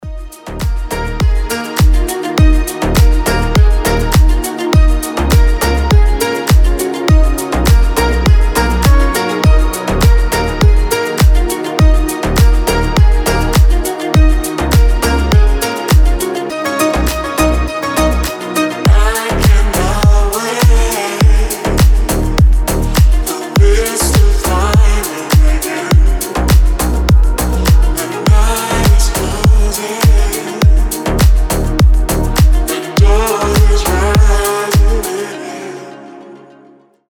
гитара
deep house
спокойные
красивая мелодия
чувственные
расслабляющие
Ничёшный дипчик